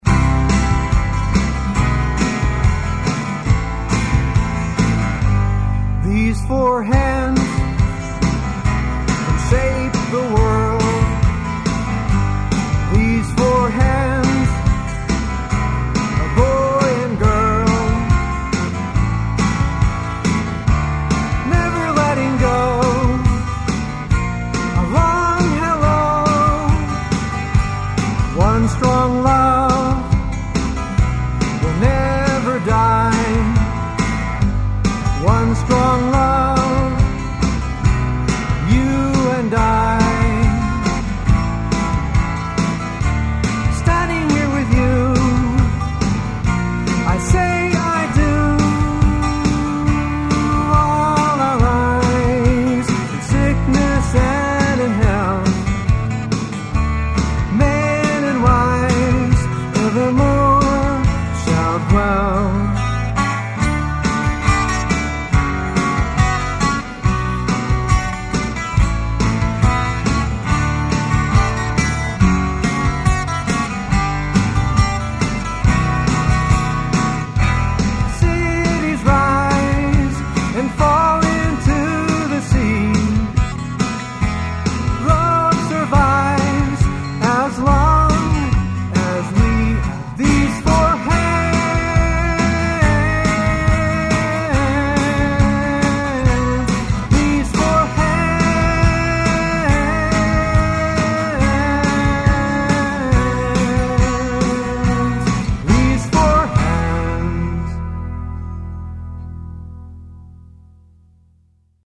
A different kind of wedding song.